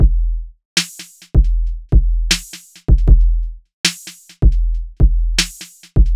TUPAC MURDER CONFESSION DRUMS.wav